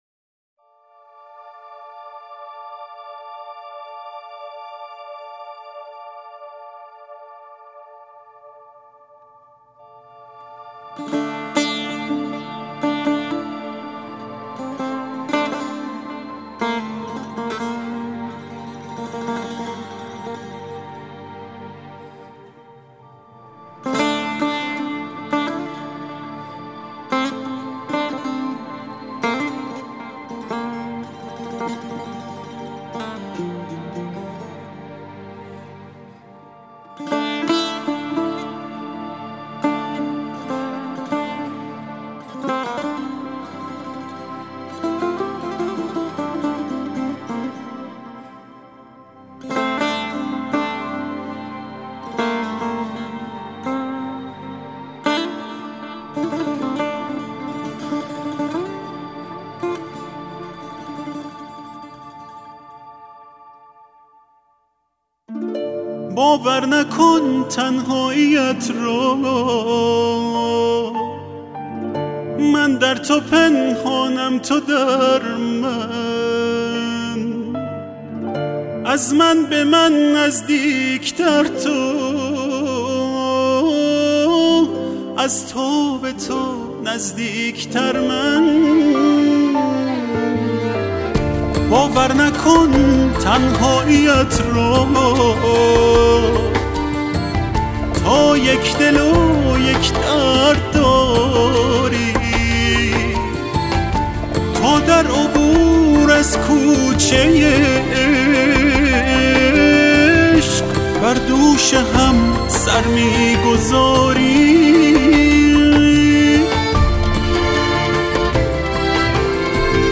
мусиқӣ